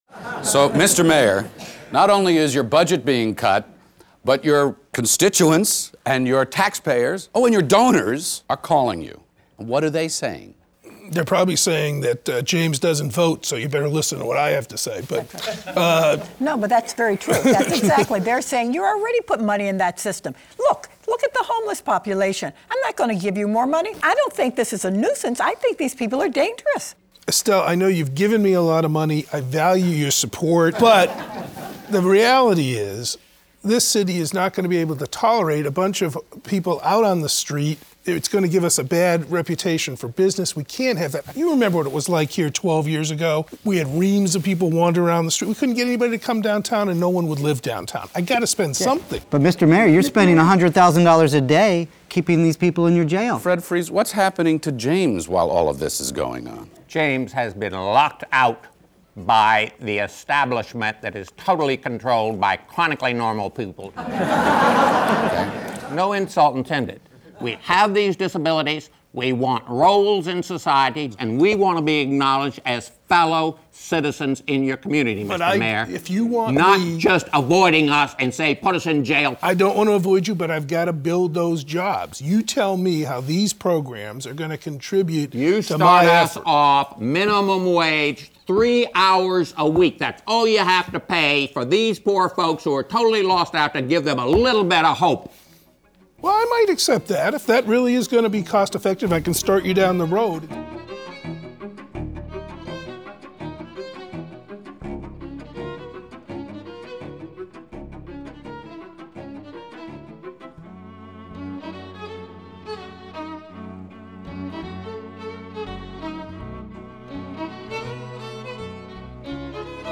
Frank Sesno moderates an engaging conversation sparked by a diverse panel including: Supreme Court Justice Stephen Breyer, Nobel Laureate Dr. Eric Kandel and other physicians, policy makers and mental health professionals, some of whom themselves struggle with mental illness.